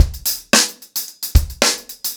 TupidCow-110BPM.17.wav